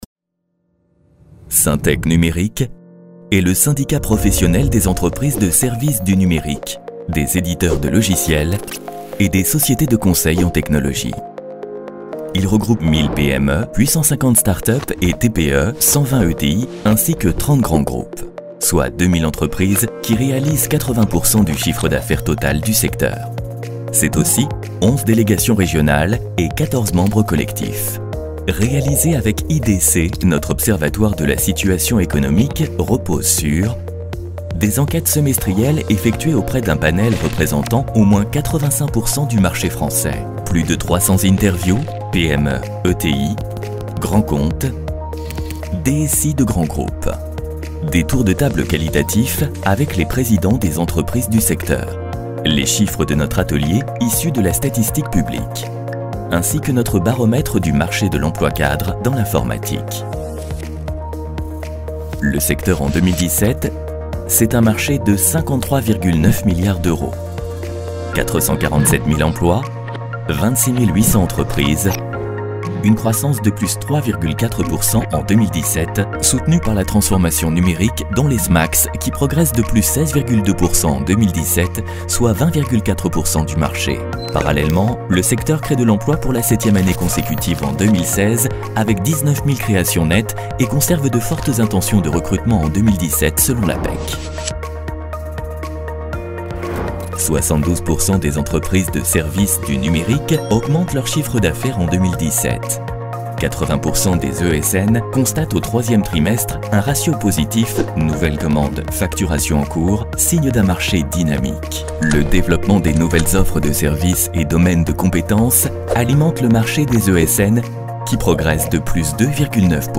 Voix off motion design
Une voix claire, maîtrisée, au service du mouvement et de l’idée.
1. SYNTEC droit, informatif 4:09